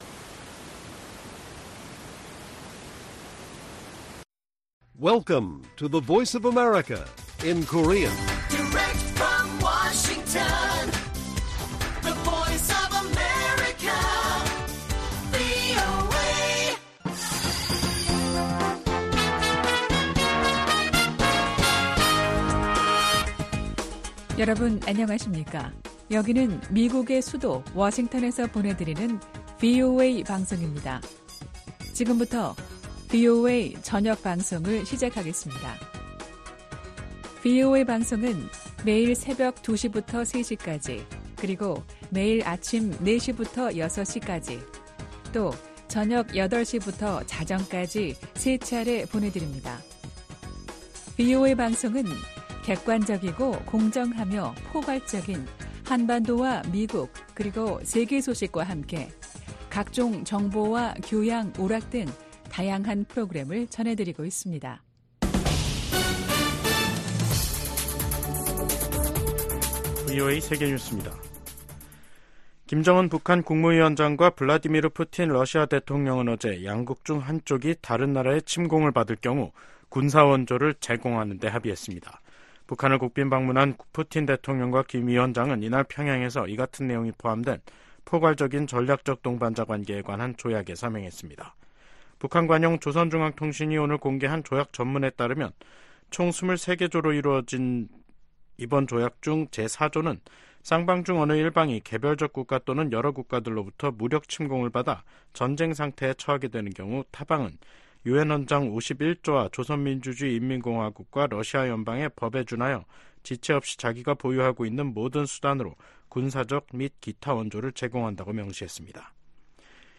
VOA 한국어 간판 뉴스 프로그램 '뉴스 투데이', 2024년 6월 20일 1부 방송입니다. 북한과 러시아가 새 조약을 통해 어느 한쪽이 무력침공을 받아 전쟁 상태에 놓이면 지체 없이 군사적 원조를 제공하기로 했습니다.